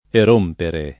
erompere
erompere [ er 1 mpere ] v.; erompo [ er 1 mpo ]